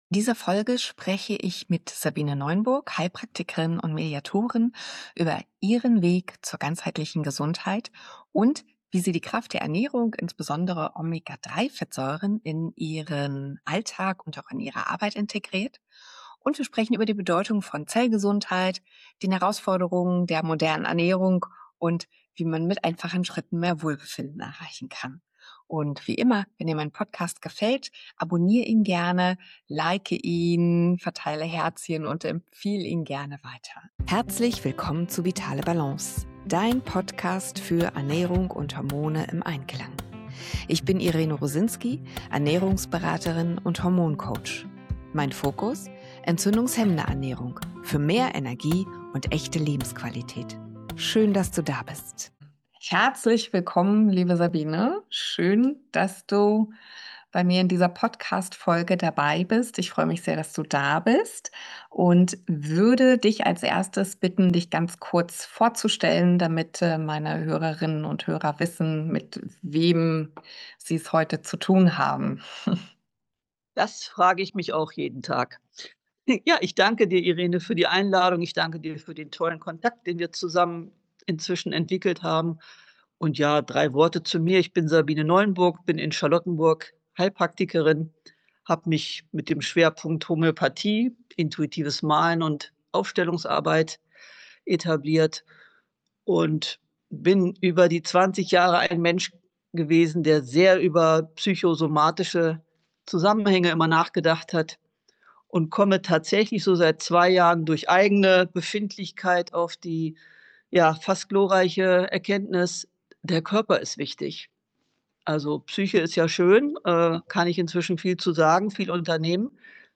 Körper & Seele im Einklang: Warum Omega-3 und Zellgesundheit den Unterschied machen – Interview